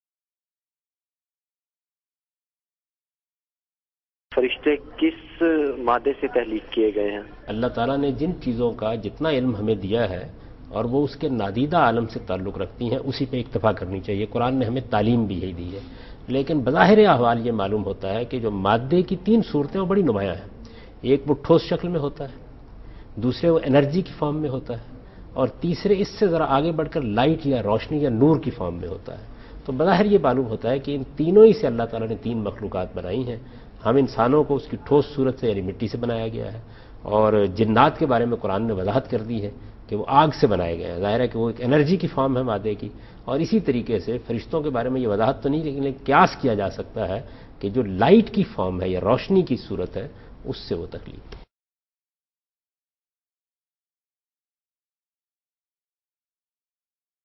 Category: TV Programs / Dunya News / Deen-o-Daanish /
Deen o Danish Question and Answer by Javed Ahmad Ghamidi in urdu